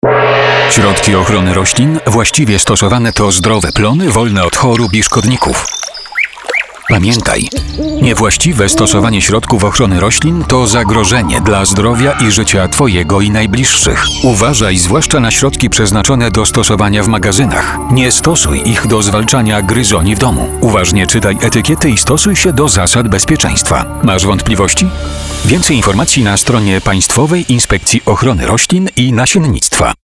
Spot radiowy PIORiN